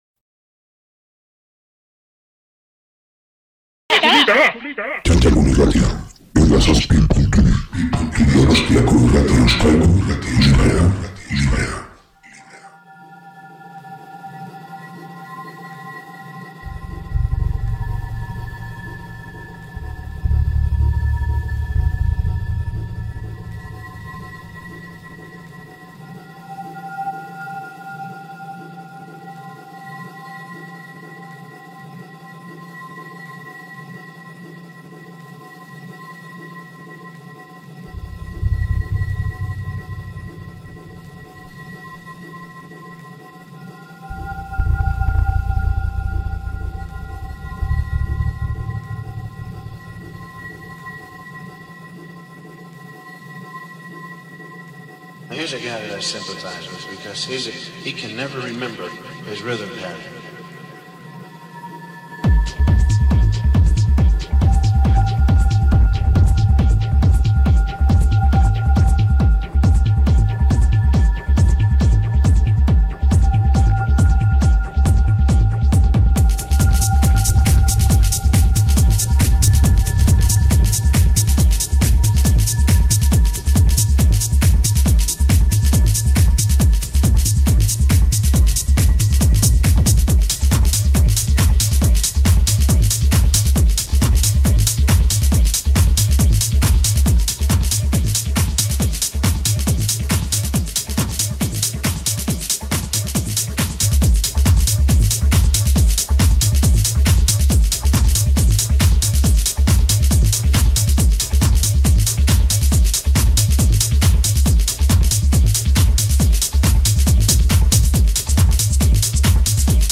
live
techno